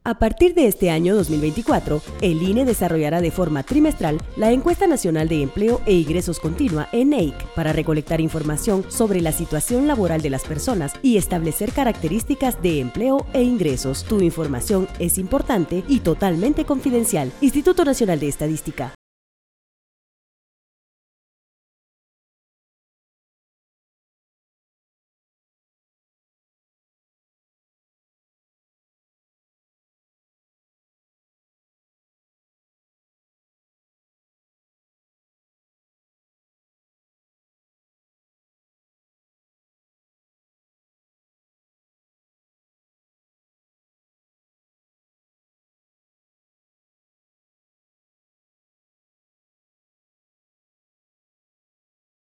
Spot de radio #4